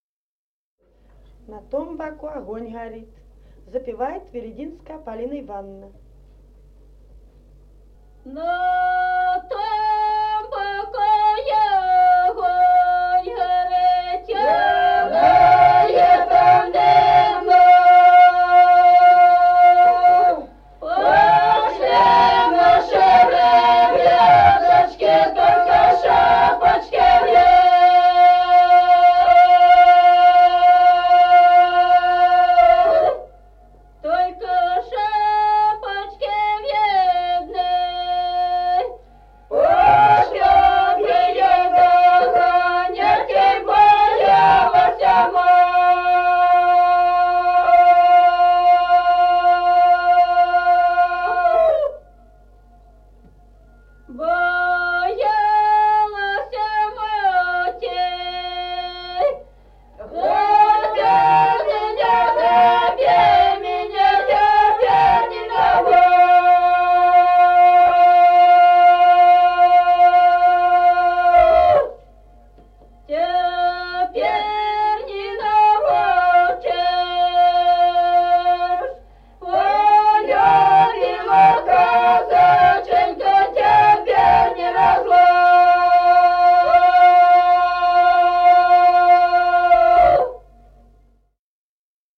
Песни села Остроглядово. На том боку огонь горит (петровочная).